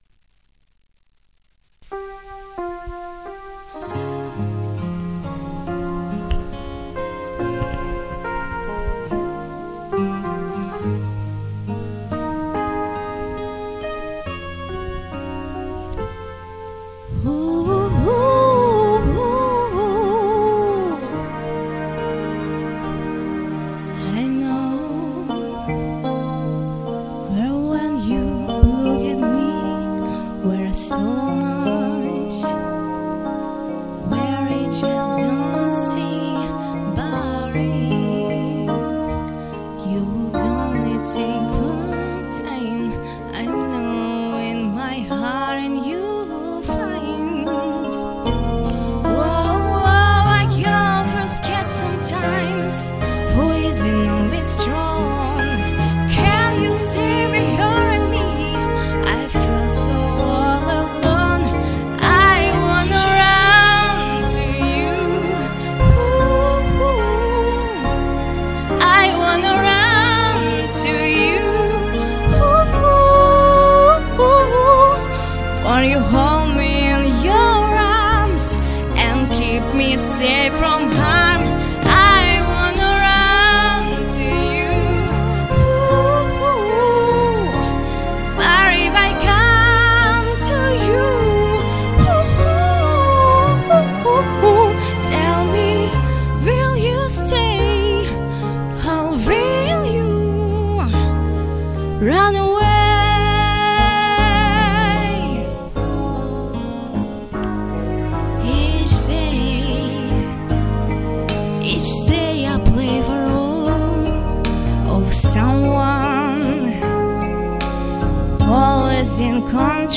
Обе соперницы спели шикарно!
Красивое, нежное, порою даже невесомое исполнение.